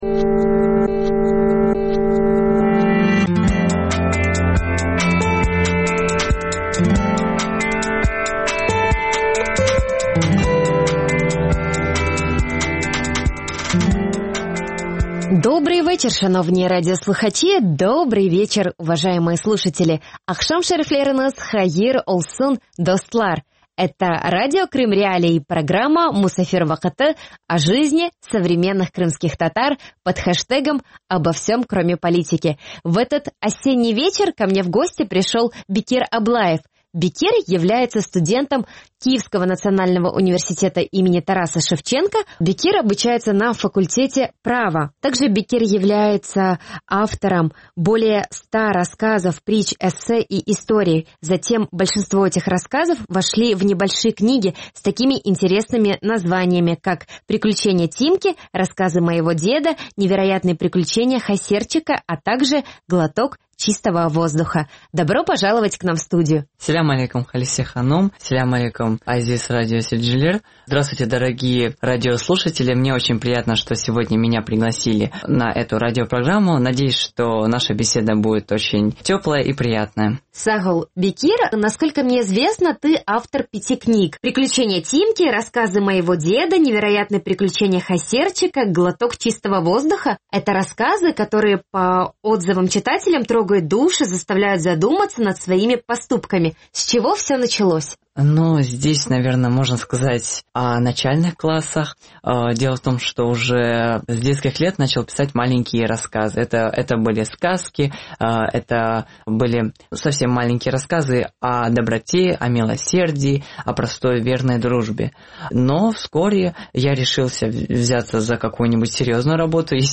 Він розповість про написані оповідання з власного життя, а також про нову книгу, яку невдовзі зможуть побачити кияни.